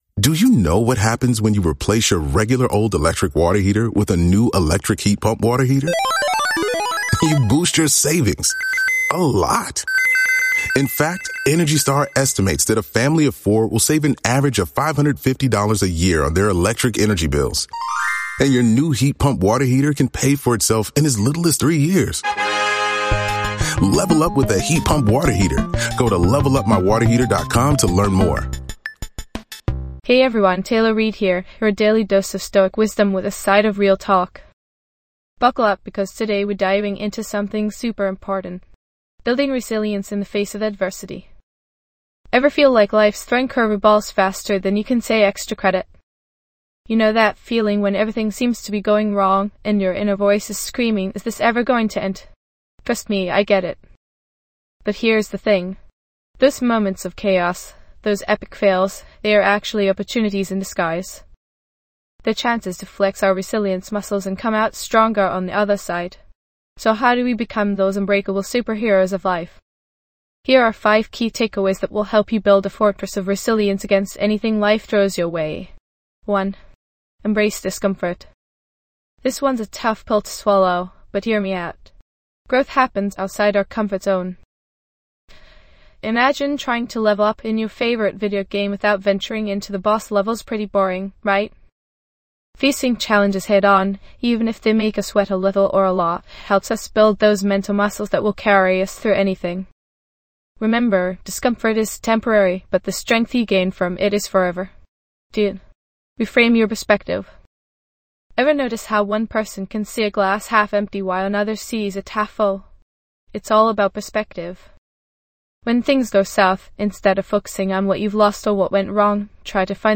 Self-Help & Personal Development Philosophy & Spirituality Mental Health & Wellness Inspirational Talks
This podcast is created with the help of advanced AI to deliver thoughtful affirmations and positive messages just for you.